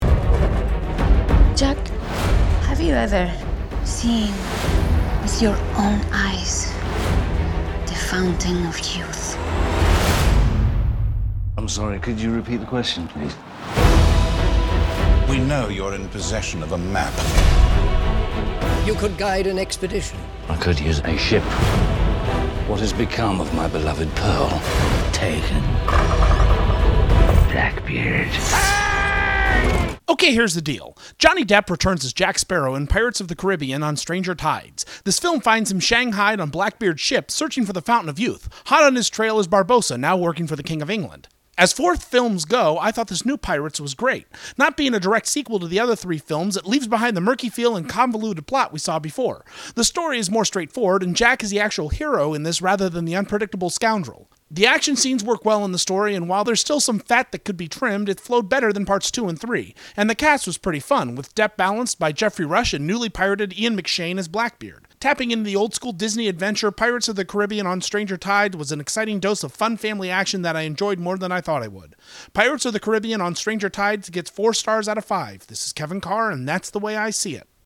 'Pirates of the Caribbean: On Stranger Tides' Movie Review